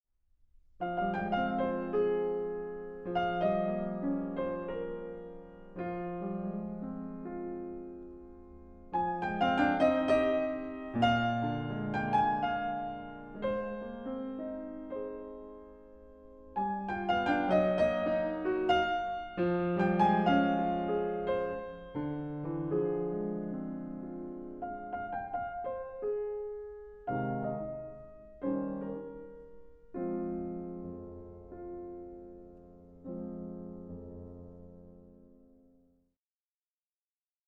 Přestože první ukázka nabízela jen nástrojovou artikulaci tohoto hudebního tématu, její vznosný a ušlechtilý melodicko-harmonický průběh prokazuje velice blízké vztahy s kantilénovým charakterem zpěvákova přednesu a jeho procítěnou dynamickou škálou.